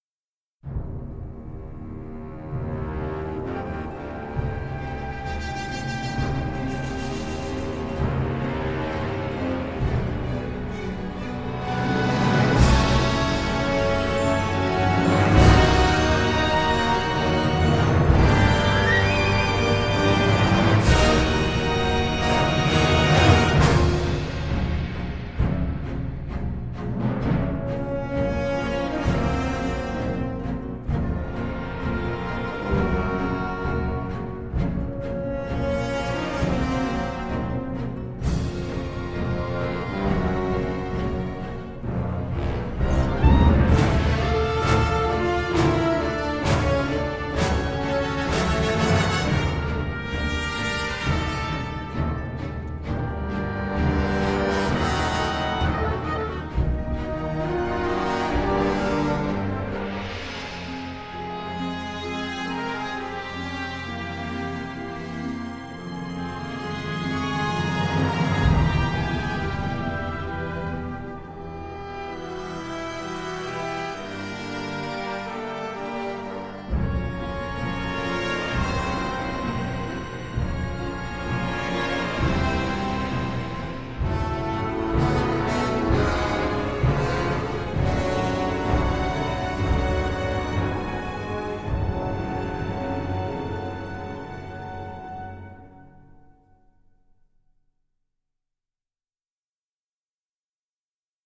vif, enjoué, rentre-dedans, festif, fou…